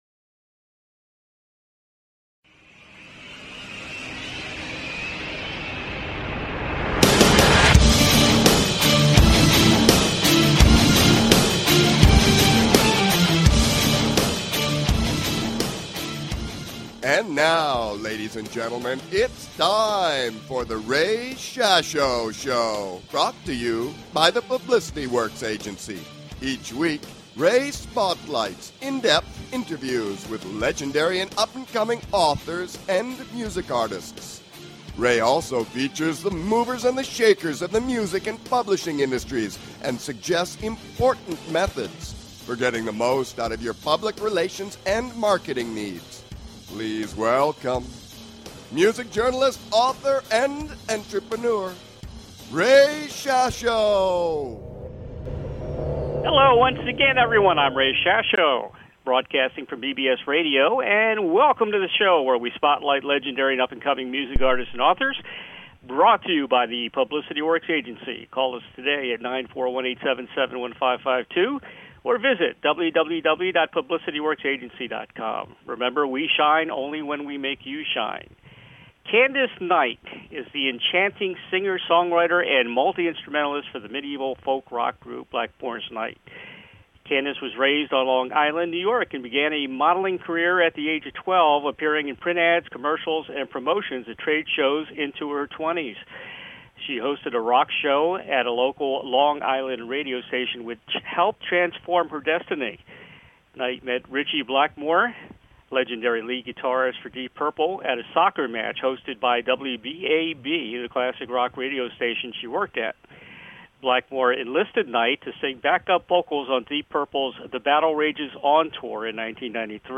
Guest, Candice Night